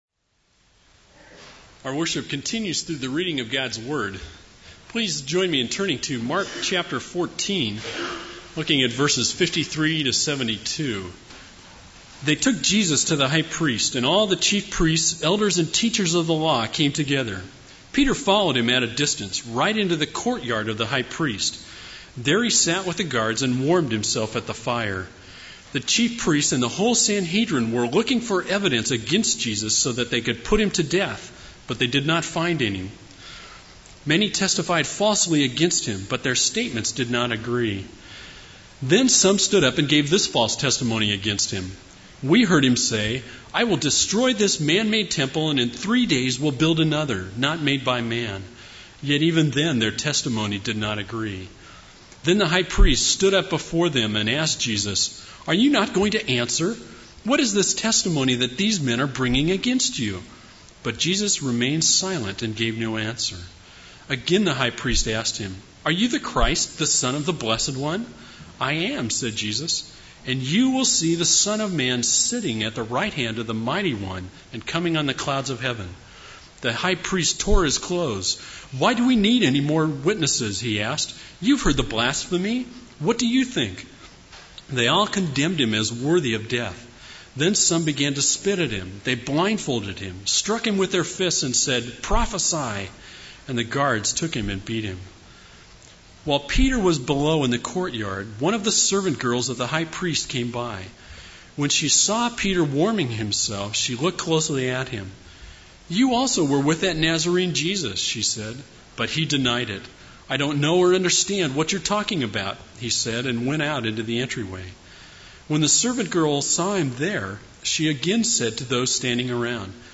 This is a sermon on Mark 14:53-72.